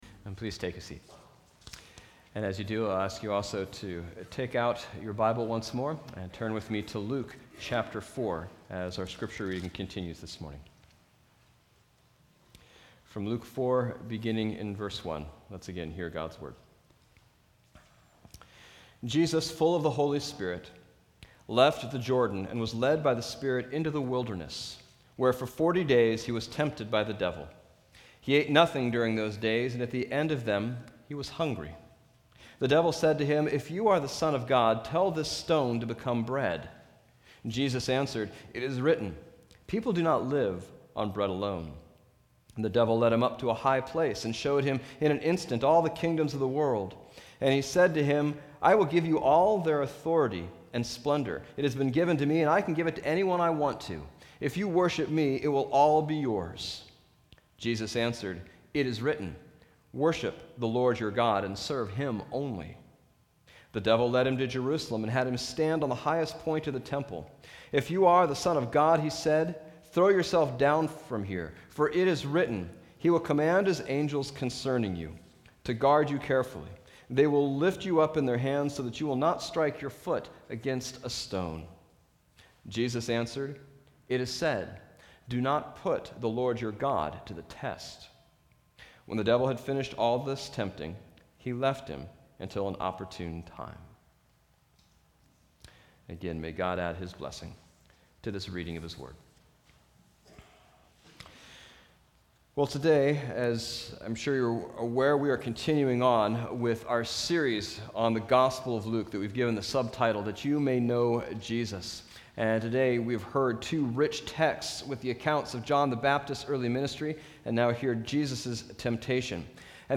Bible Text: Luke 3:1 - 4:13 | Preacher: